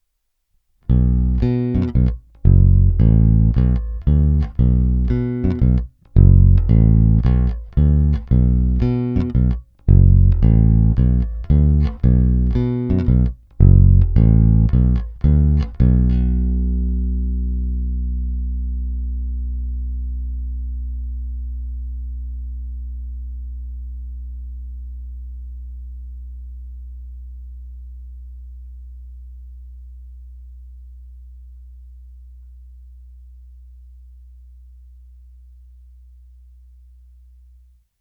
I přestože jsou na base půl roku staré struny, což u Elixirů v zásadě nic neznamená, je slyšet, že nové snímače mají o něco mohutnější basy a brilantnější výšky, než ty původní mexické.
Snímač u krku – původní